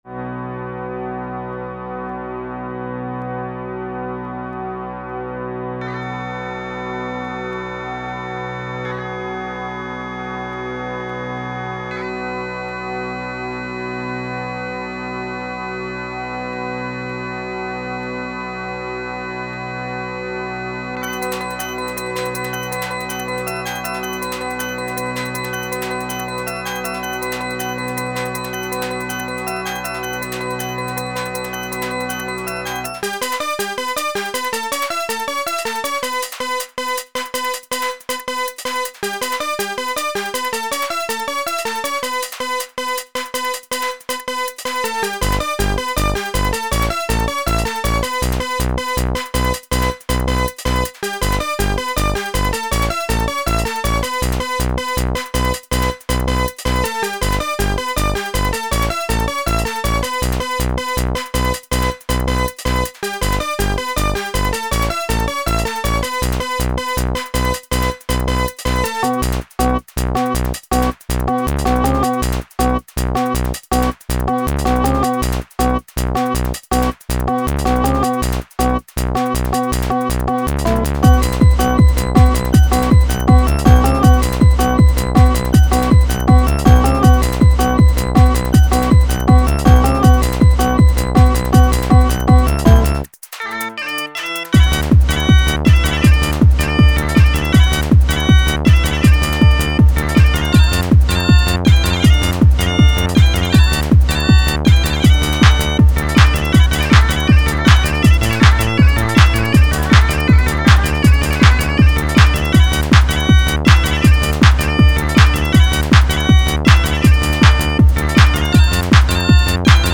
[instrumental mix]
• Quality: 44kHz, Stereo